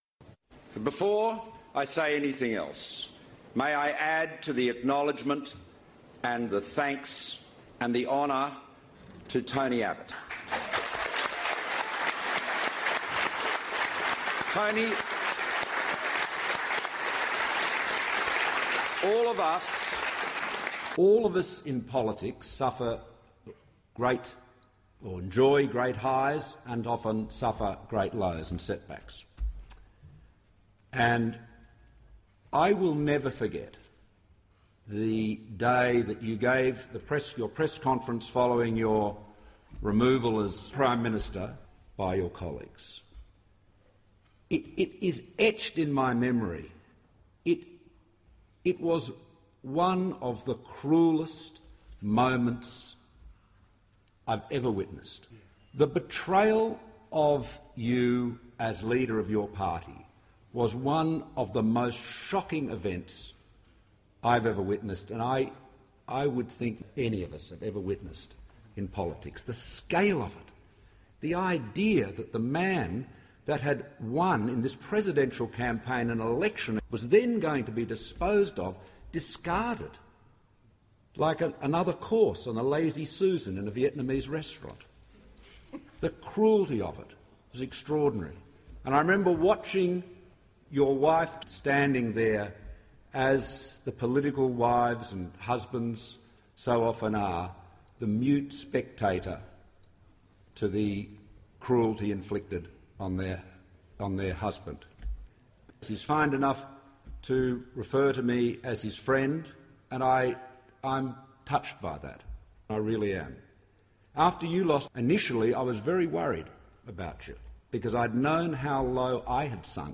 Malcolm Turnbull - in praise of former PM Tony Abbott (may be digitally altered)